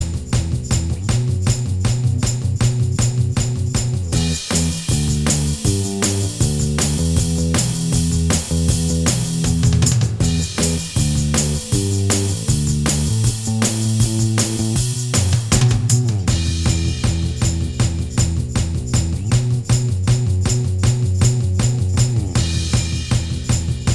Minus Guitars And Sitar Rock 4:00 Buy £1.50